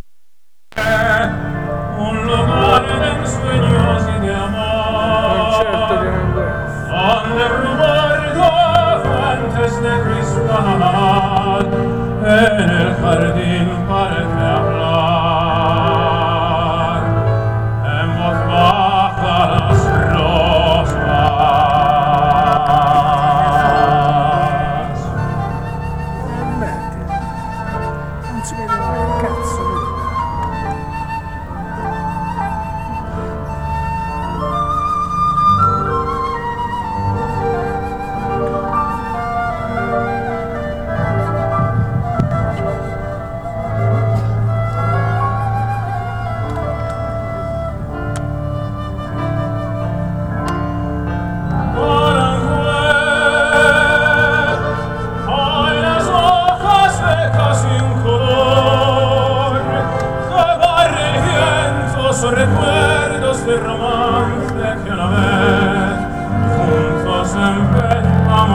Alcune delle musiche dal vivo